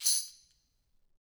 Tamb1-Shake_v1_rr2_Sum.wav